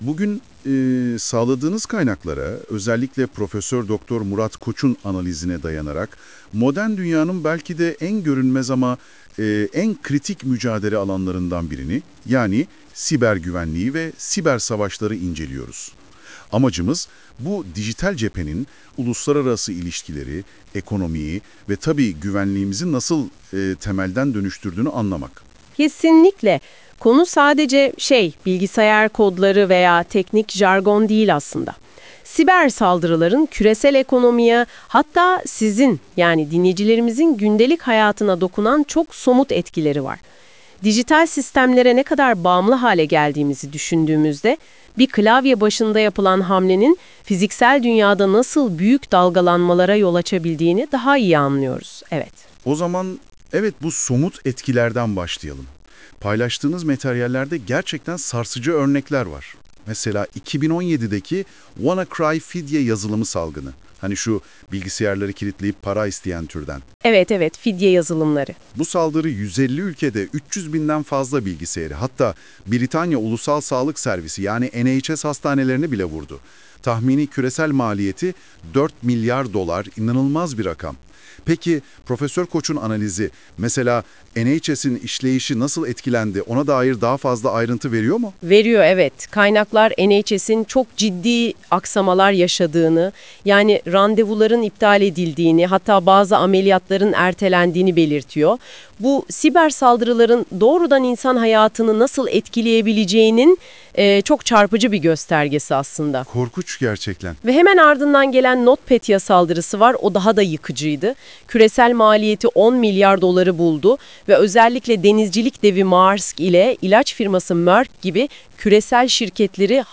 Yapay zeka AI ile yapılan sesli versiyonu